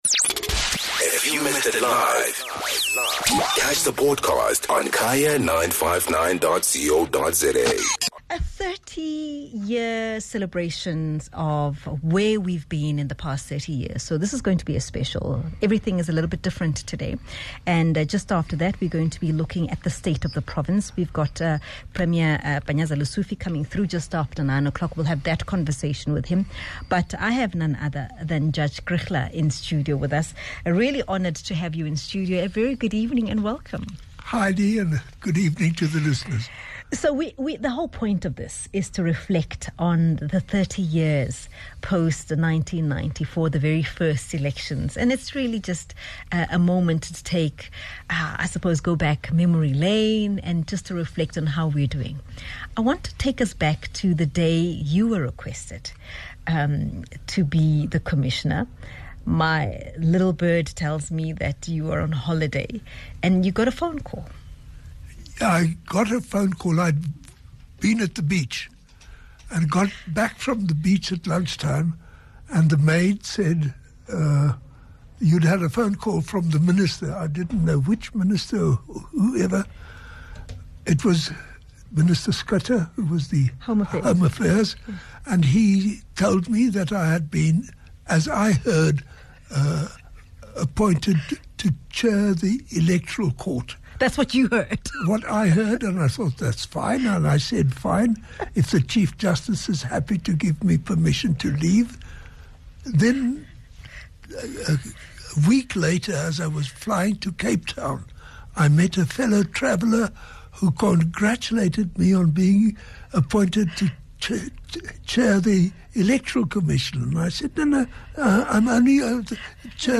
With South Africa getting closer to the 2024 general elections, Judge Johann Kriegler who was appointed a Justice of the Constitutional Court on its foundation in 1994 is in the studio important to reflect on some of the more pronounced constitutional and legislative amendments since 1994.